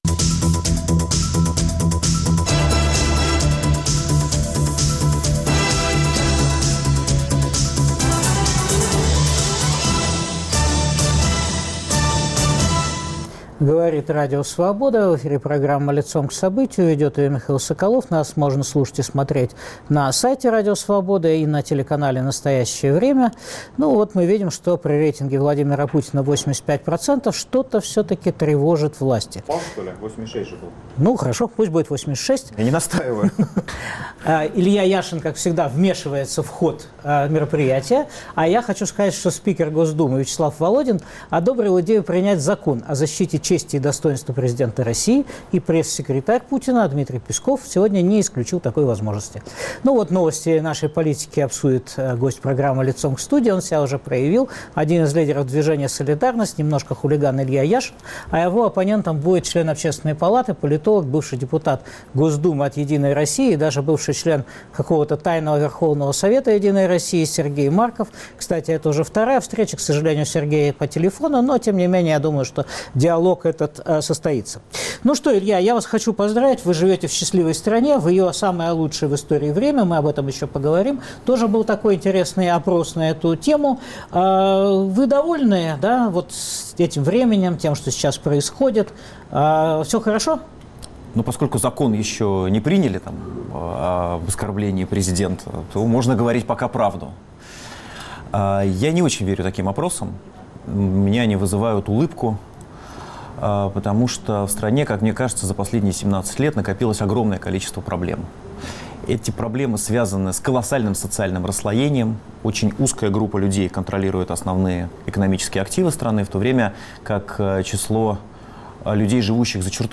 Лидер не вечен, устойчив ли режим? Илья Яшин дискутирует с Сергеем Марковым.